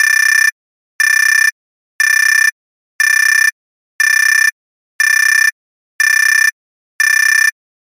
Warning Alarm Buzzer